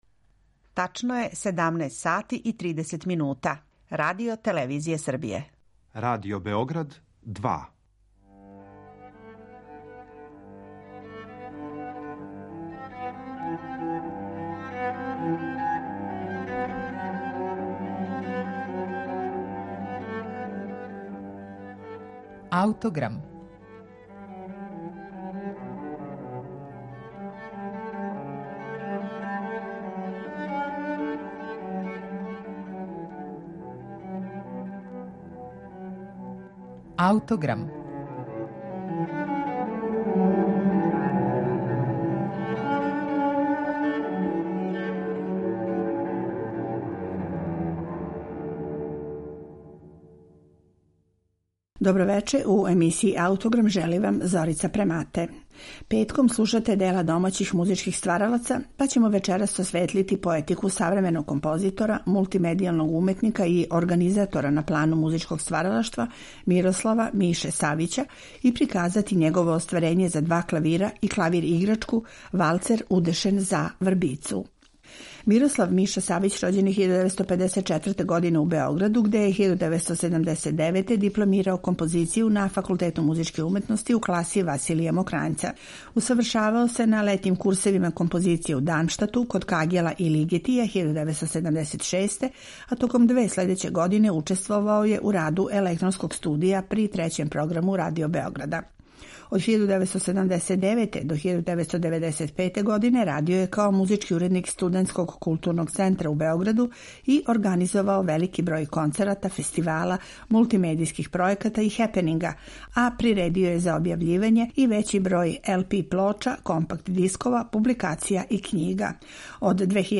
Данас ће бити речи о стилу овог нашег композитора, минималисте, концептуалисте и видео-уметника, а поменути духовити и меланхолични валцер представићемо у верзији за клавирски дуо и клавир-играчку. Снимак је са концерта у њујоршком „Kарнеги Холу", а свирају чланови „ЛП дуа" и Маргарет Ленг Тан.